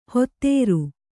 ♪ hottēru